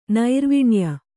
♪ nairviṇya